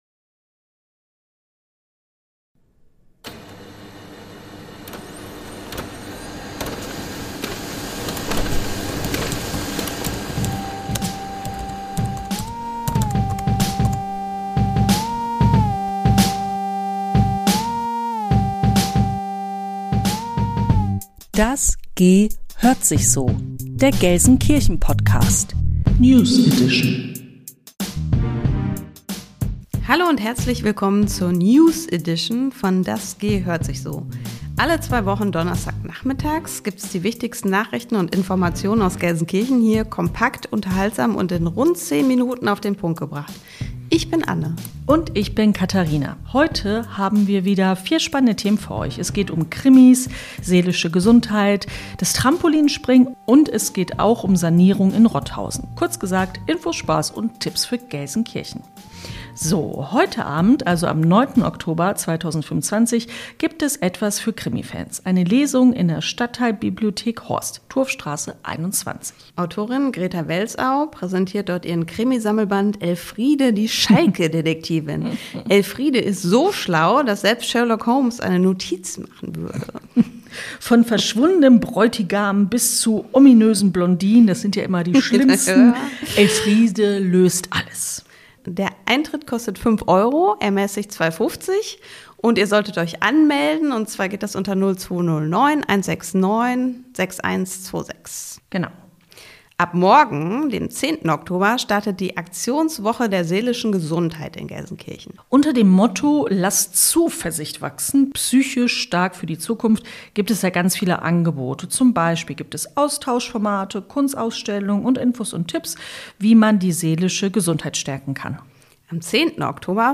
Nachrichten aus Deiner Stadt - direkt ins Ohr